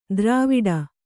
♪ drāviḍa